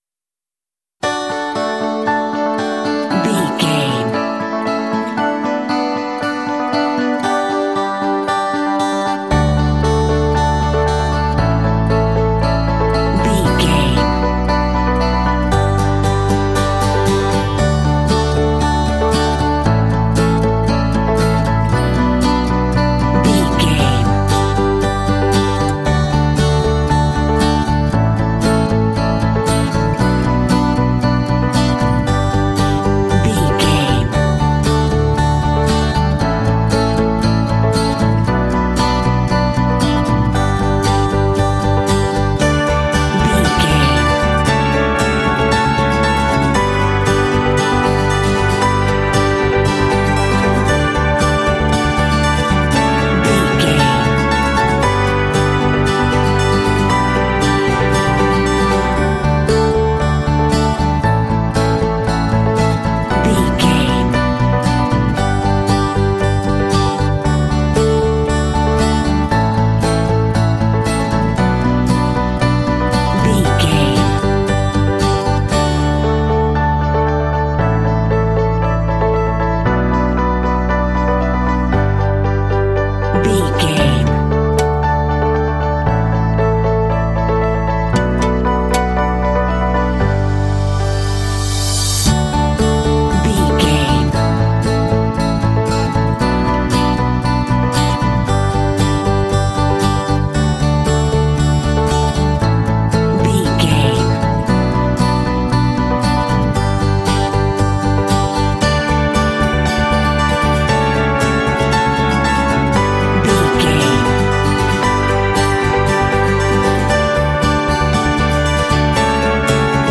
Uplifting
Ionian/Major
D
optimistic
happy
piano
acoustic guitar
strings
bass guitar
rock
contemporary underscore
indie